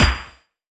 hitBigPlayer.wav